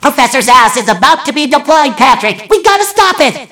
mvm_bomb_alerts14.mp3